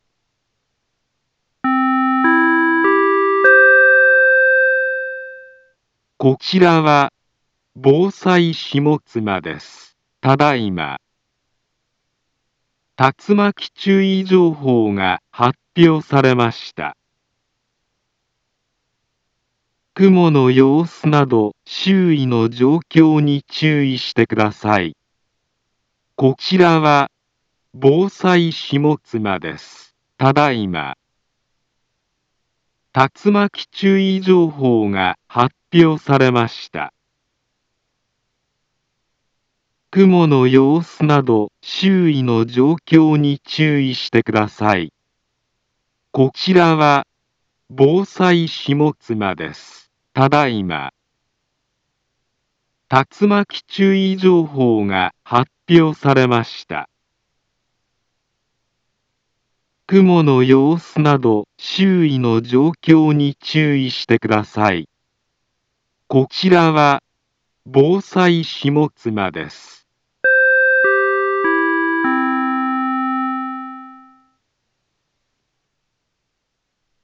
Back Home Ｊアラート情報 音声放送 再生 災害情報 カテゴリ：J-ALERT 登録日時：2021-08-30 21:35:10 インフォメーション：茨城県北部、南部は、竜巻などの激しい突風が発生しやすい気象状況になっています。